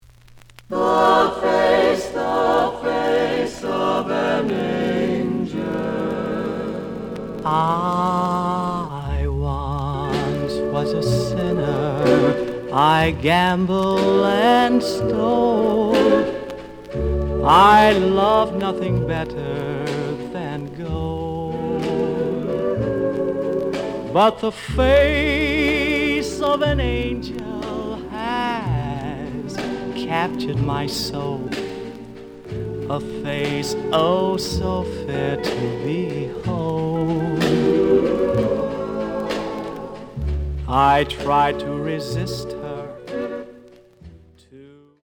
試聴は実際のレコードから録音しています。
●Genre: Rhythm And Blues / Rock 'n' Roll
EX-, VG+ → 傷、ノイズが多少あるが、おおむね良い。